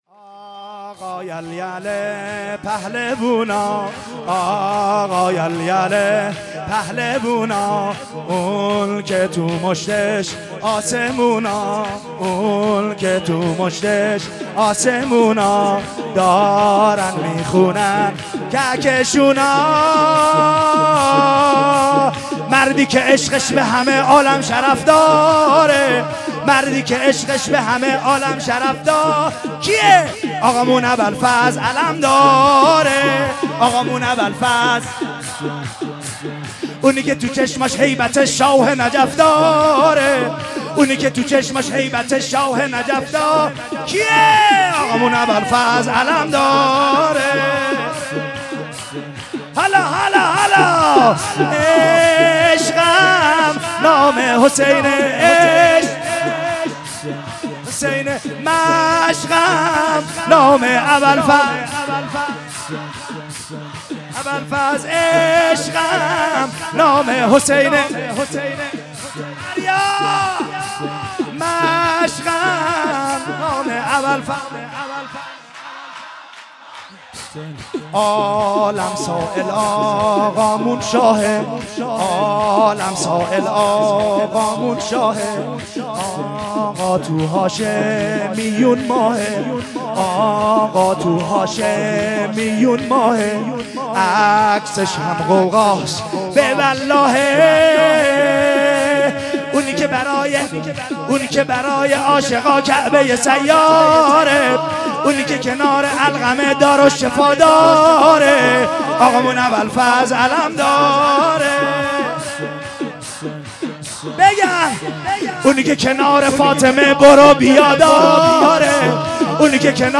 سرود- آقا یل یل پهلوونا
جشن کوثر ولایت15